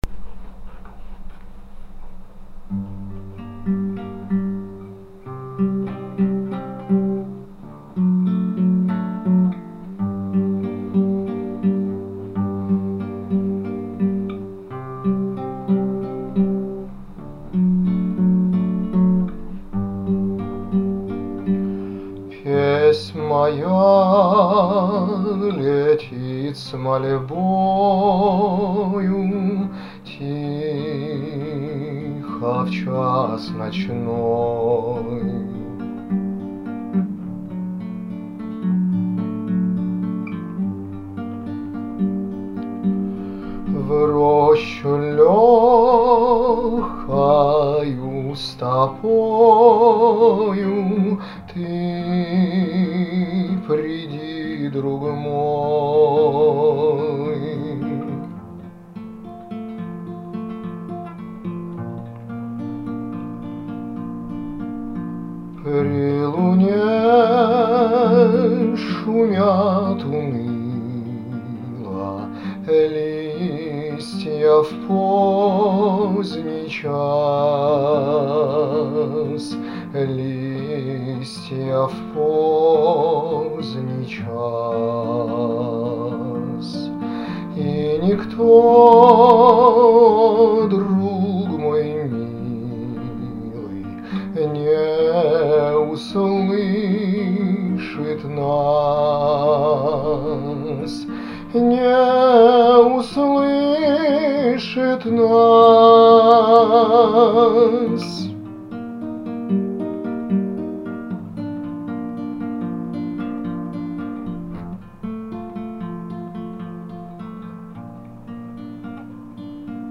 • Жанр: Классика